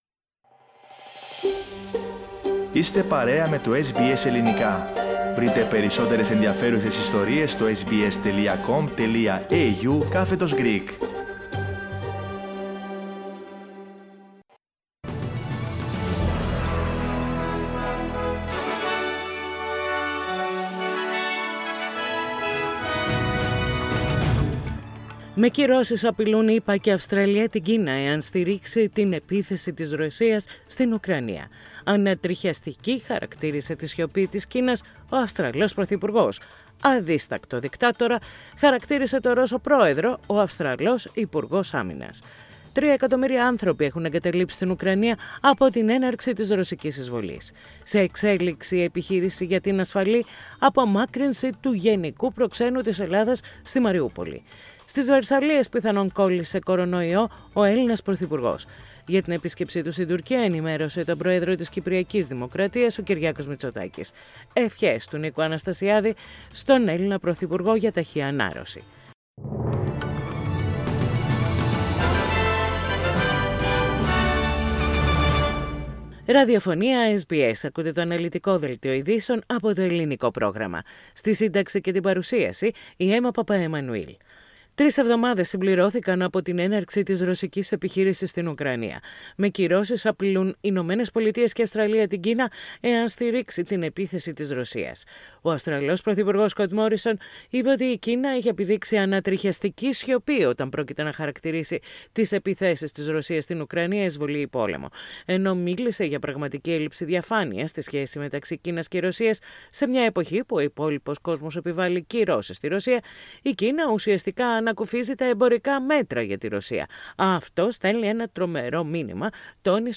Δελτίο Ειδήσεων - Τετάρτη 16.3.22
News in Greek. Source: SBS Radio